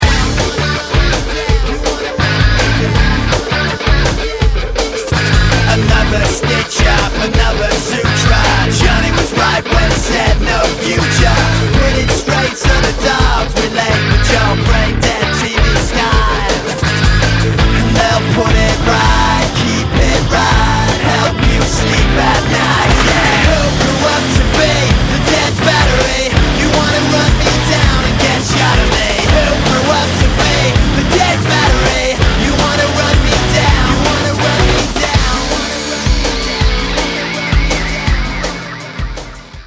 thick guitars, breakneck breakbeats